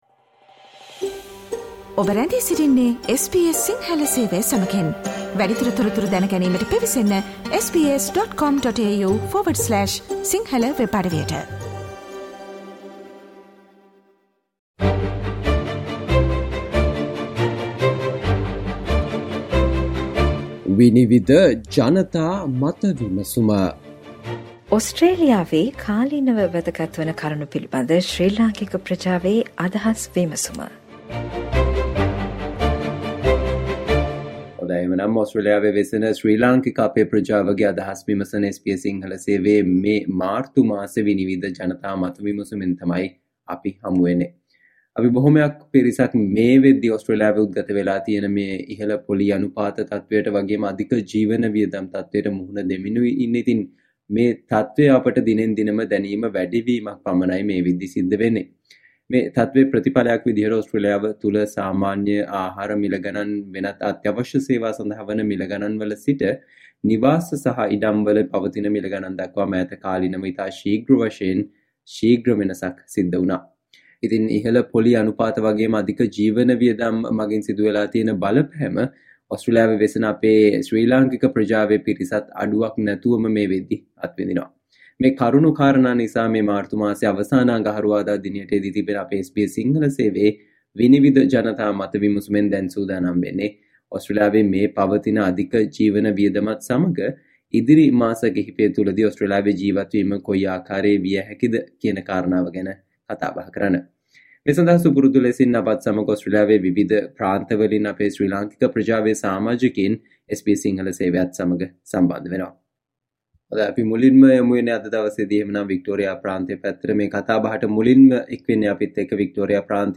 ඕස්ට්‍රේලියාවේ මේවනවිට පවතින අධික ජීවන වියදමත් සමග ඉදිරි මාස කිහිපය තුළදී ඕස්ට්‍රේලියාවේ ජීවත් වීම කෙබඳු විය හැකිද සහ මේ සම්බන්ධයෙන් අදාළ බලධාරීන් මගින් සිදු විය යුතු දේ පිළිබඳව ඕස්ට්‍රේලියාවේ වෙසෙන ලාංකිකයින්ගේ අදහස් වලට සවන් දෙන්න මෙම මාර්තු මාසයේ SBS සිංහල ගුවන් විදුලියේ 'විනිවිද' ජනතා මත විමසුම තුළින්